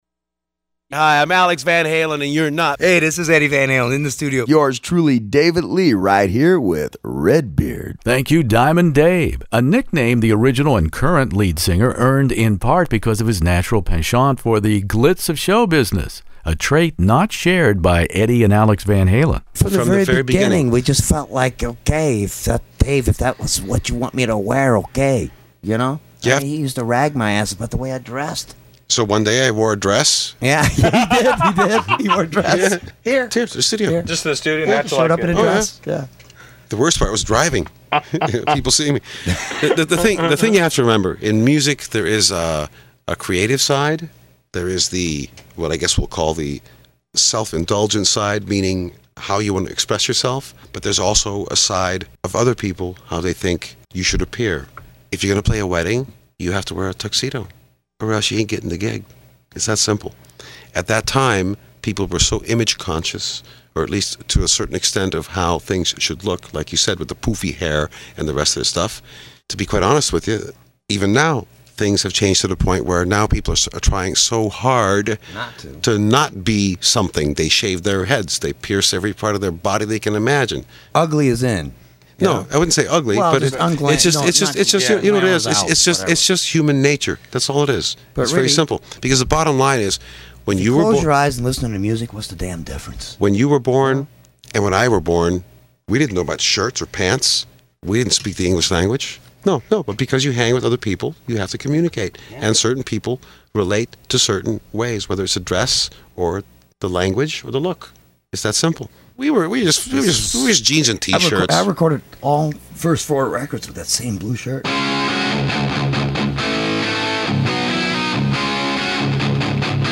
Van Halen Fair Warning interview Eddie Van Halen